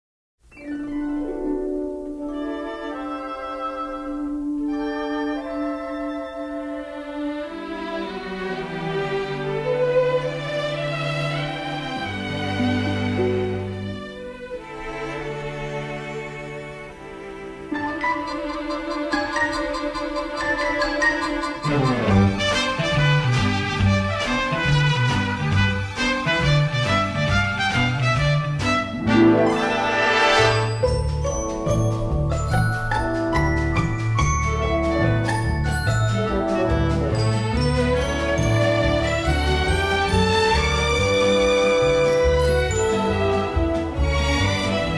released in stereo in 1959